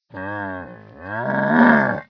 c_camel_atk1.wav